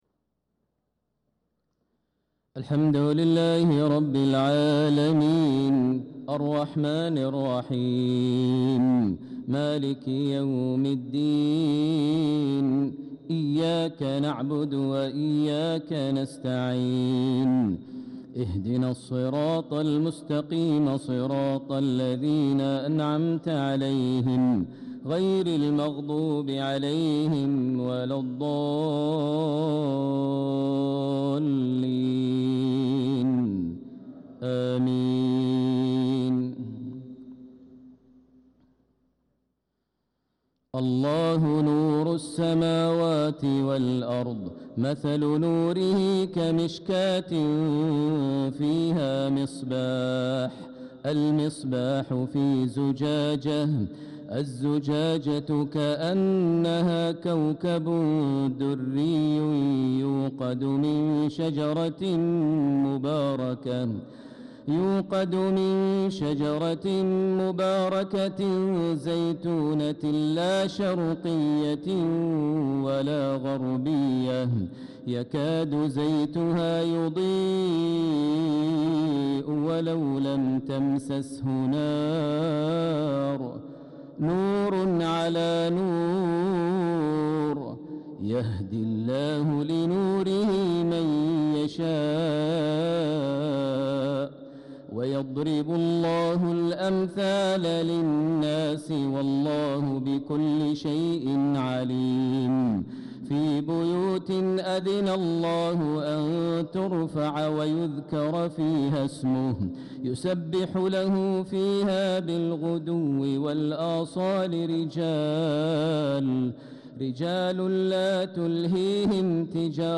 صلاة العشاء للقارئ ماهر المعيقلي 20 ذو الحجة 1445 هـ
تِلَاوَات الْحَرَمَيْن .